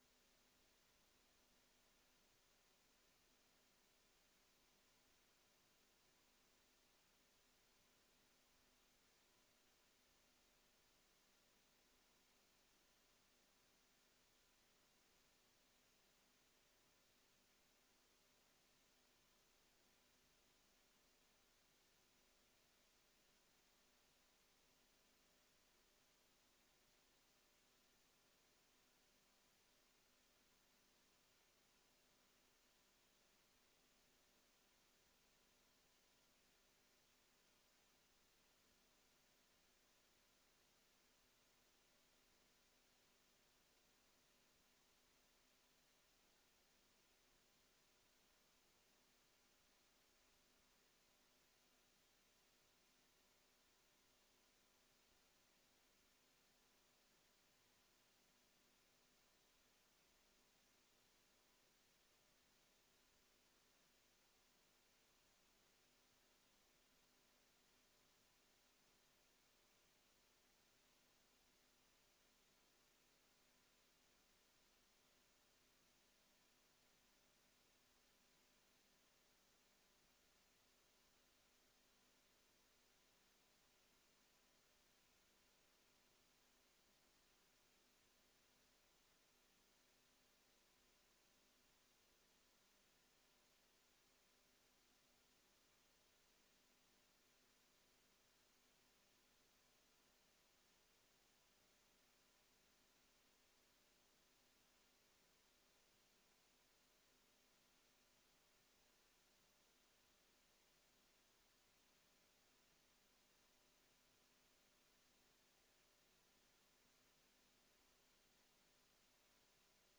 Gemeenteraad 24 september 2024 20:00:00, Gemeente Woerden
Locatie: Raadzaal